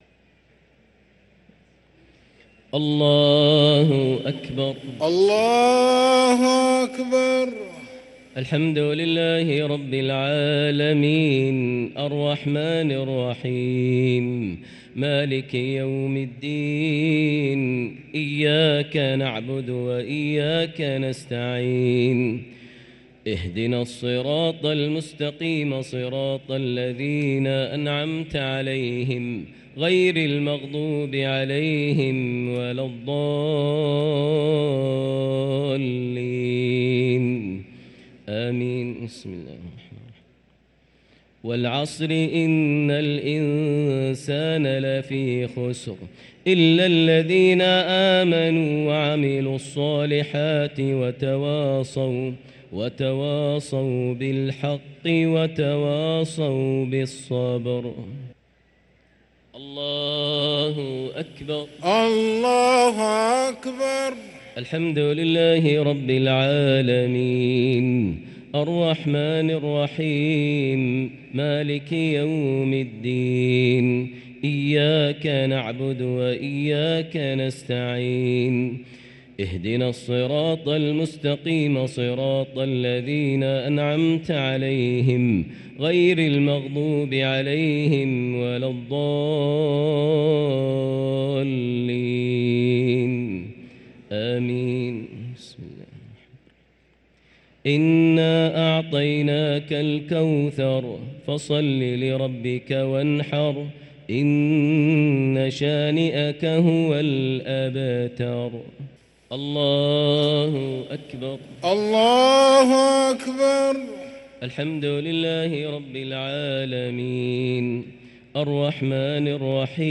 الشفع والوتر ليلة 20 رمضان 1444هـ > تراويح 1444هـ > التراويح - تلاوات ماهر المعيقلي